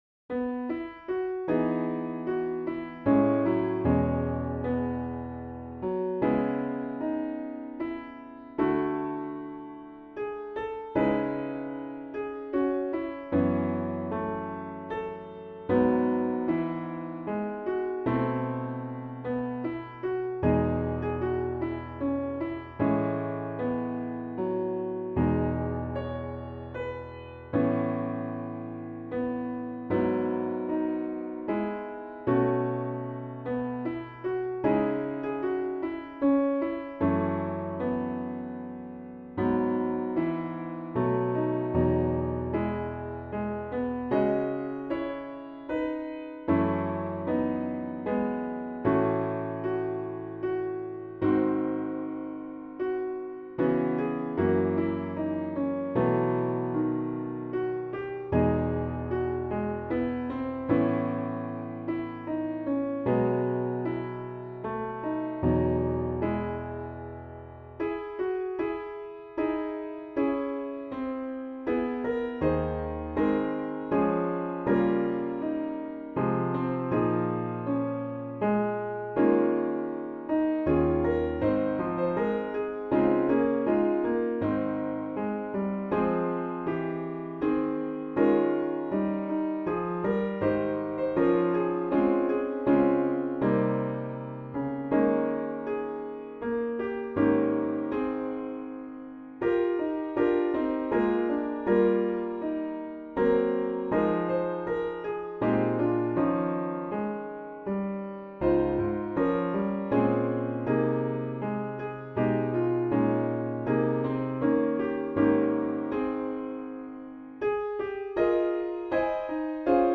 Piano Solo
Voicing/Instrumentation: Piano Solo We also have other 39 arrangements of " Lead Kindly Light ".